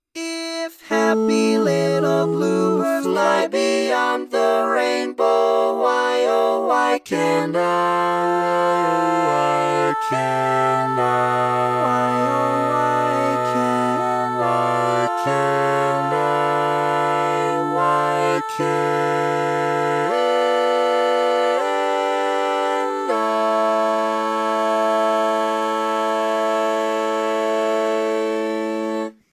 Key written in: A Major
Type: SATB